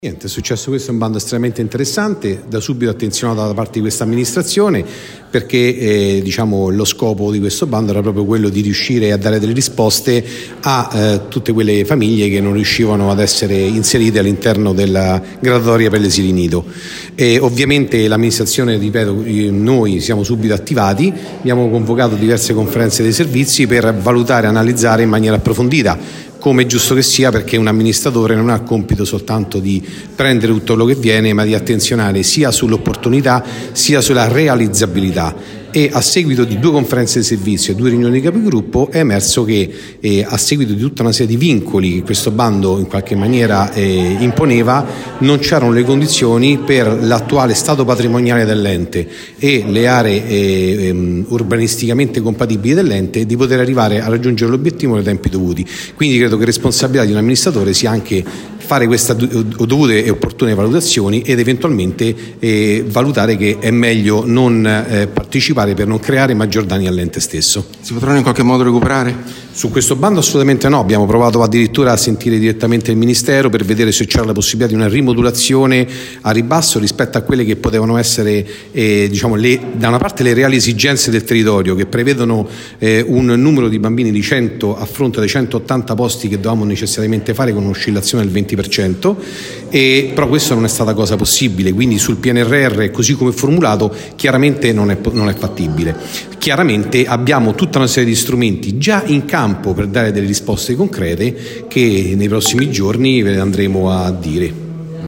LATINA – Latina non presenterà progetti per avere i fondi Pnrr destinati alla creazione di nuovi asili nido. “Bando interessante e attenzionato, ma imponeva troppi vincoli rispetto allo stato patrimoniale dell’ente, quindi per tutelare il Comune”, ha spiegato l’assessore ai LLPP Massimiliano Carnevale in una conferenza stampa che si è tenuta questa mattina.